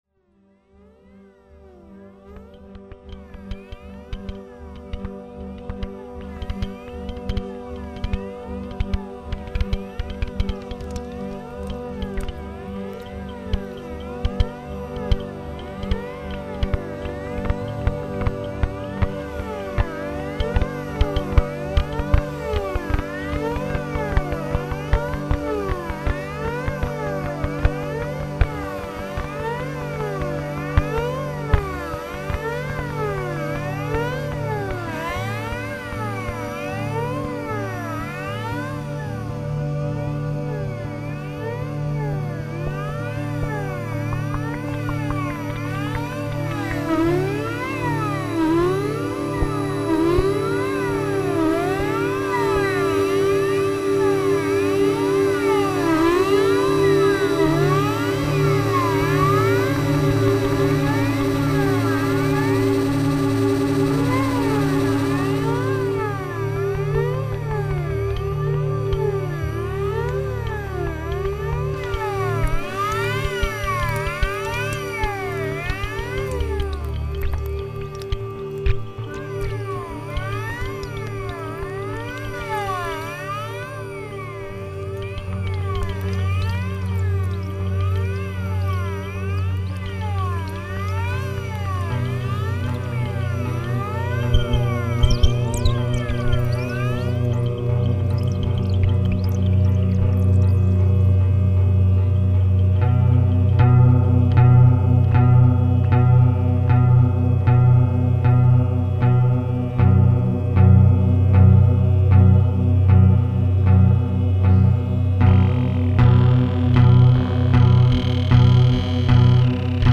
flute solo
impromptu melodica duet
manipulated organ